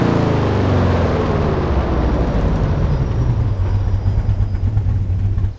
Index of /server/sound/vehicles/lwcars/dodge_daytona
slowdown_slow.wav